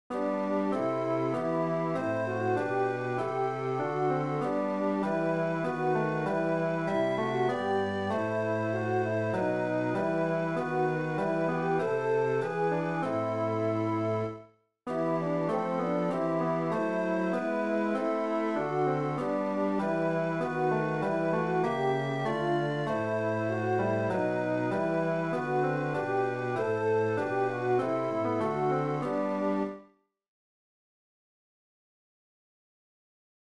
Chorproben MIDI-Files 510 midi files